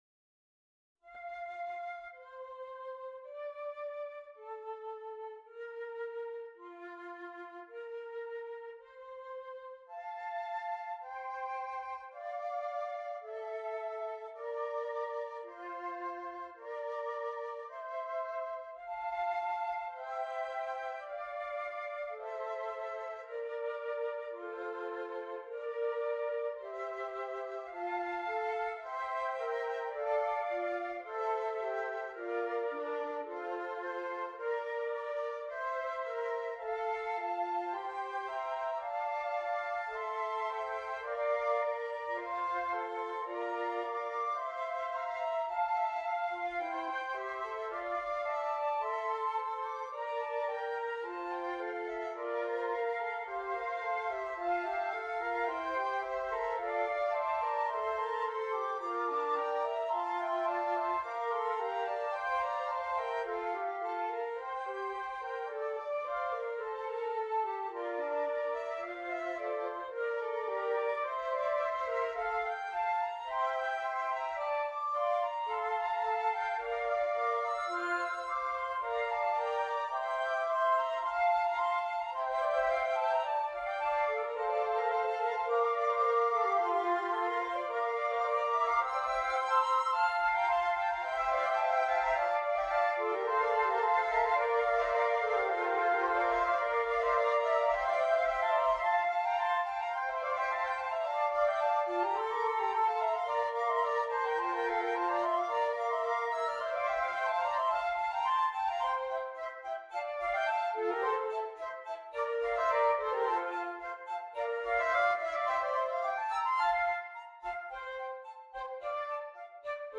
5 Flutes